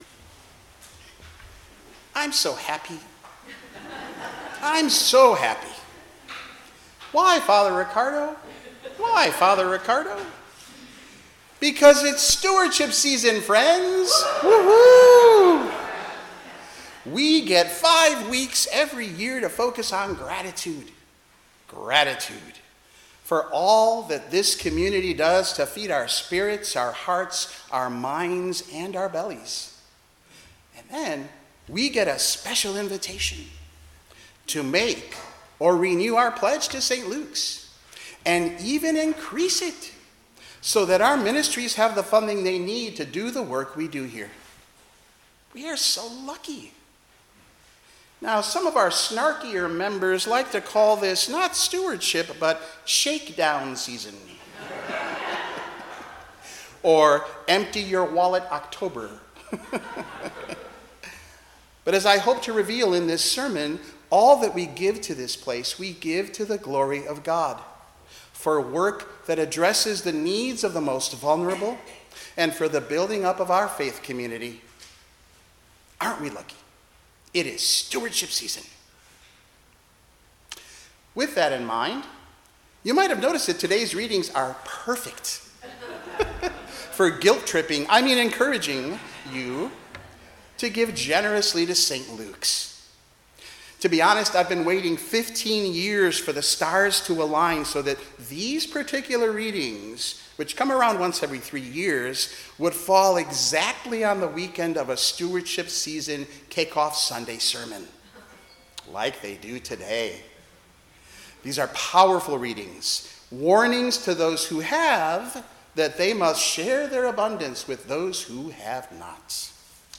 Service Type: 10:00 am Service